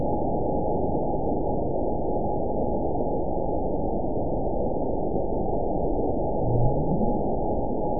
event 912323 date 03/24/22 time 20:06:17 GMT (3 years, 1 month ago) score 9.61 location TSS-AB02 detected by nrw target species NRW annotations +NRW Spectrogram: Frequency (kHz) vs. Time (s) audio not available .wav